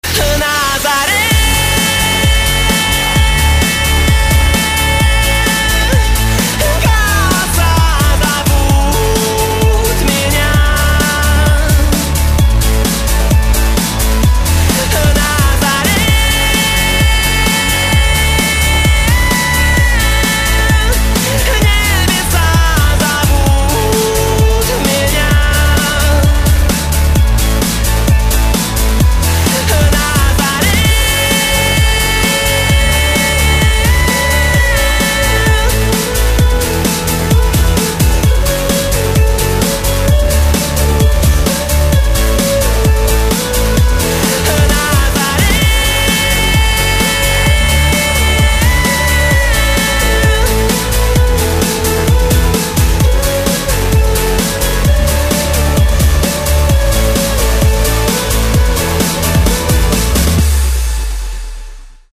• Качество: 128, Stereo
мужской вокал
танцевальная музыка